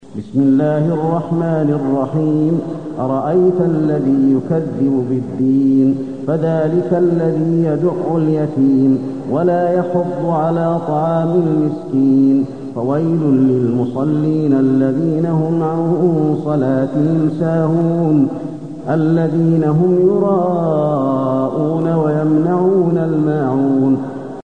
المكان: المسجد النبوي الماعون